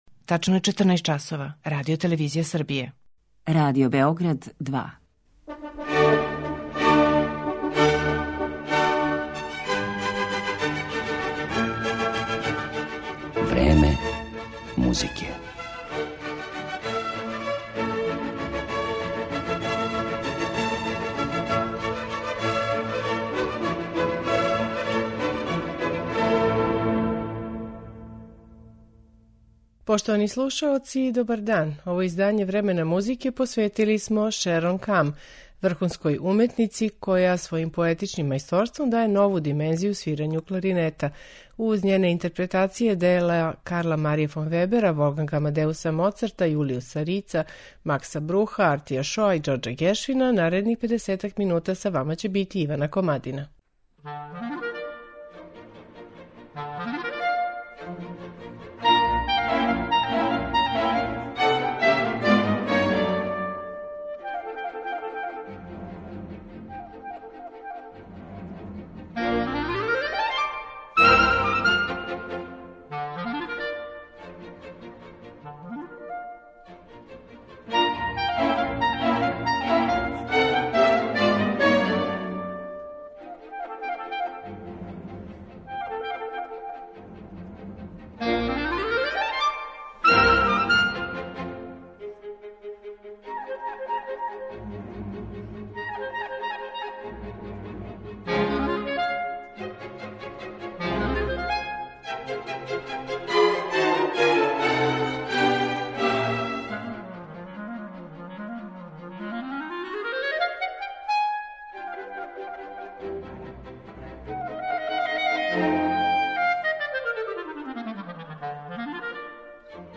Кларинетисткиња